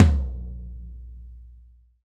TOM TOM 86.wav